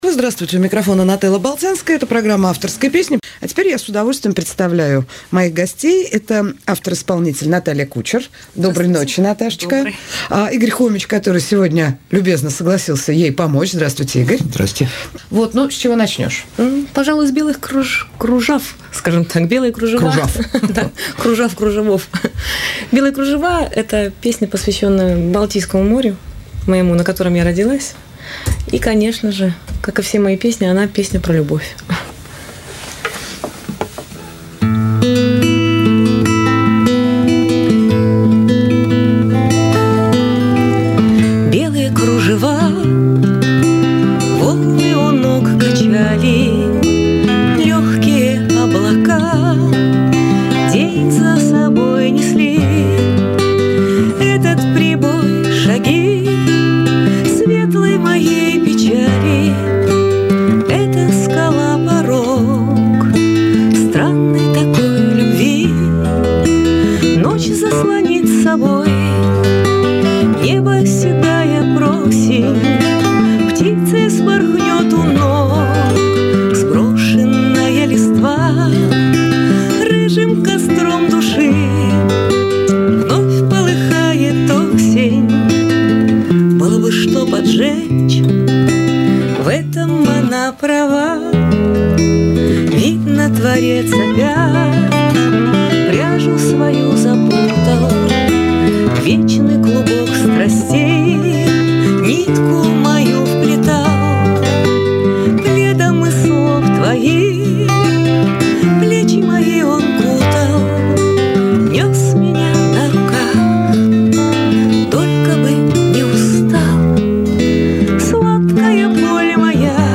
Живой эфир. "Домашник" ведёт Нателла Болтянская.